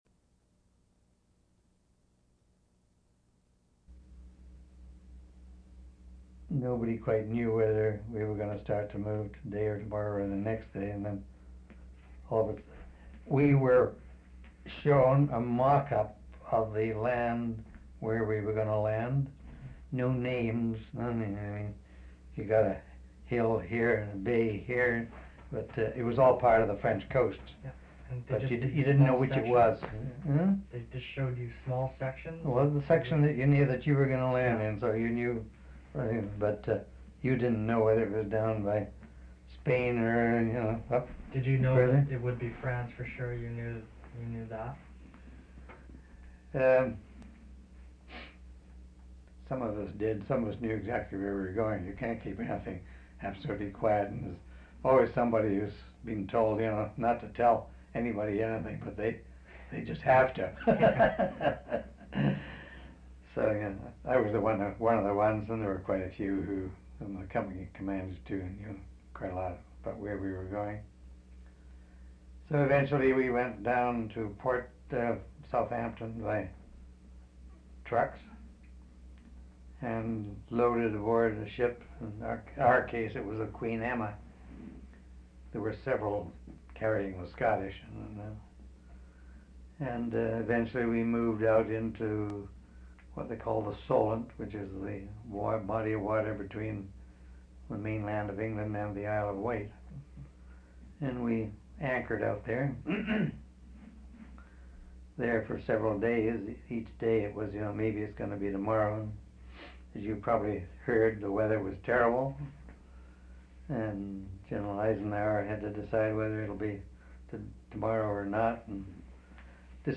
Interview took place on August 27, 1987.